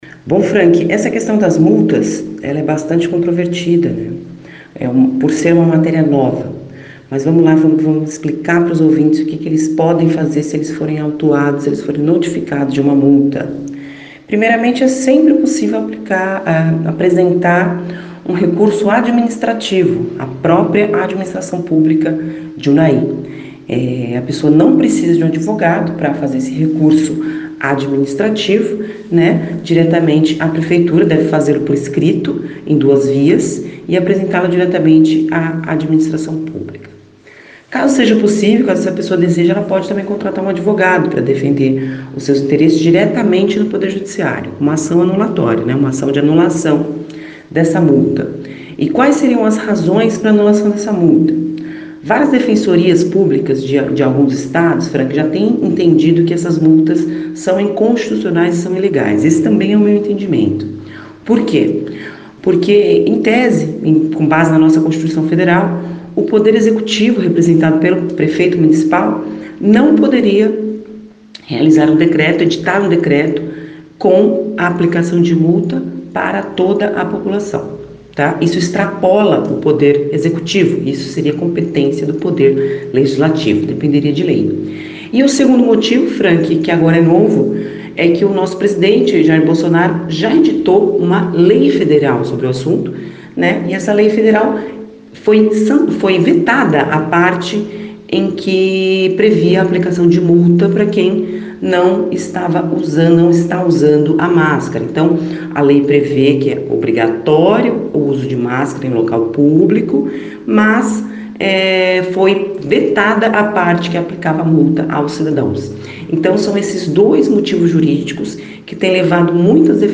Com o objetivo de esclarecer seus ouvintes e a população em geral, a Rádio Veredas ouviu, os dois lados.